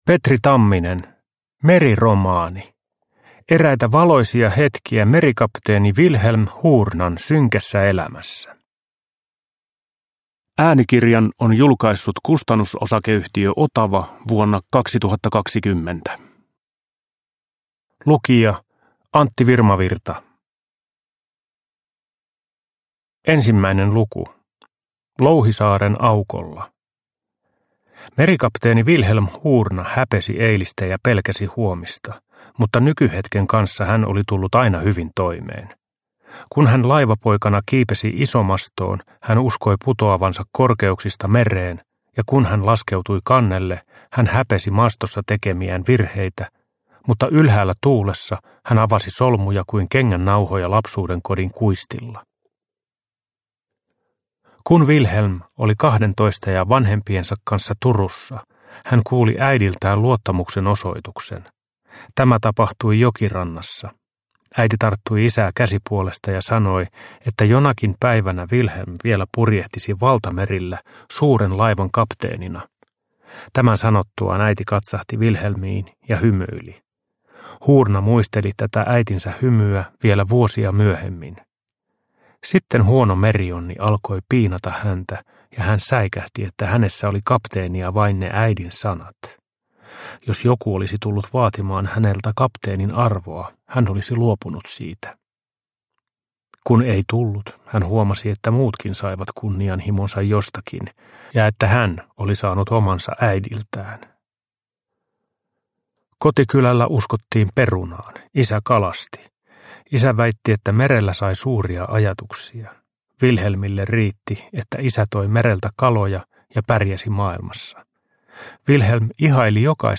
Uppläsare: Antti Virmavirta